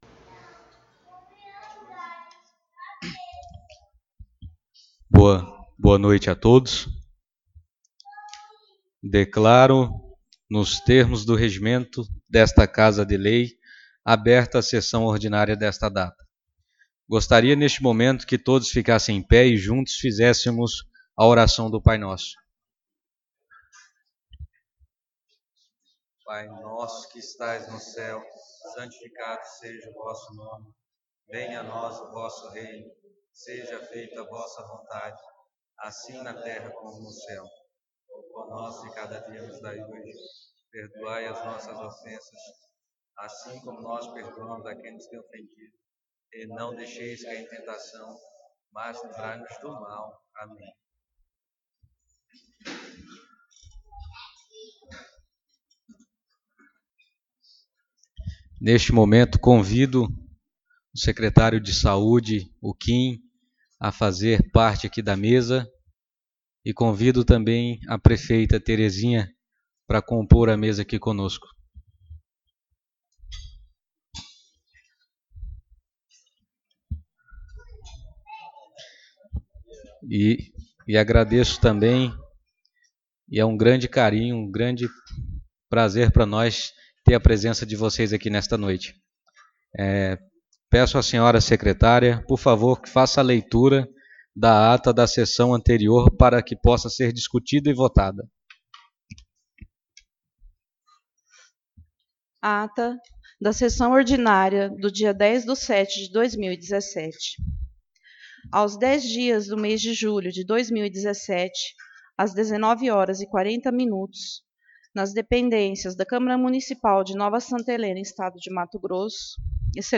Sessão Ordinária 17/07/2017